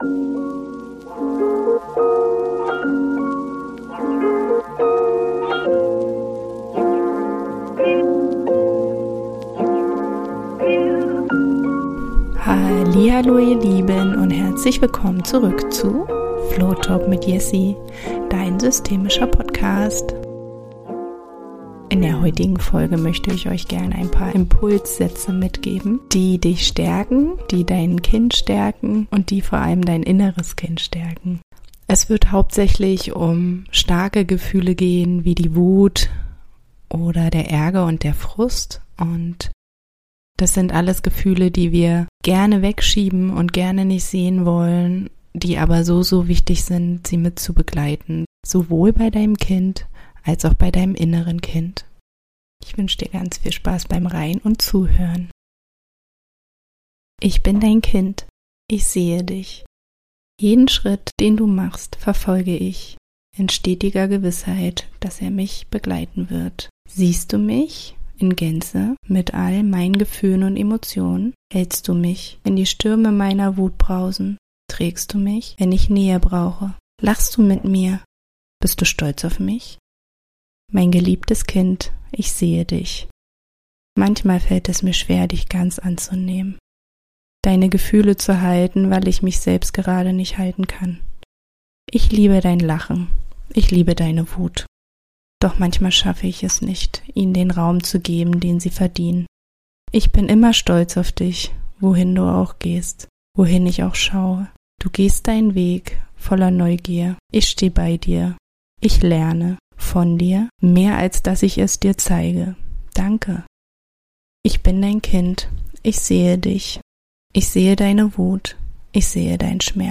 Entspannungsgeschichte für dich und dein (inneres) Kind